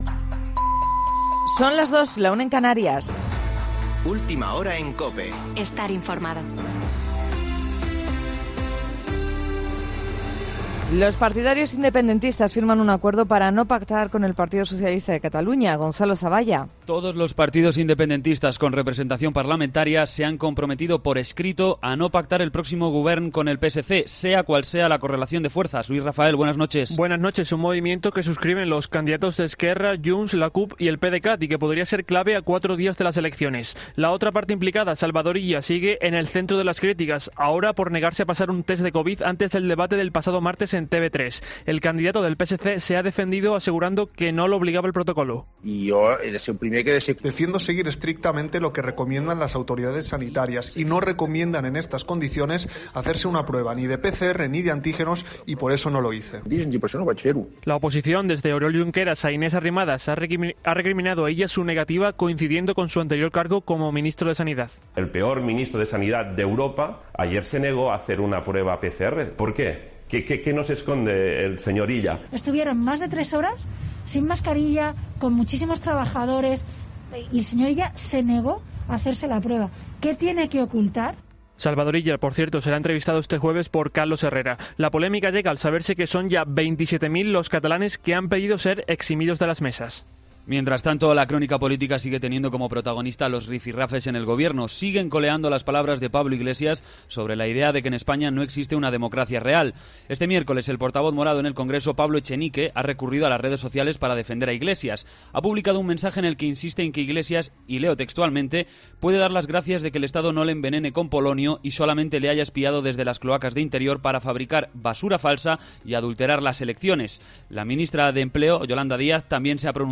Boletín de noticias COPE del 11 de febrero de 2021 a las 02.00 horas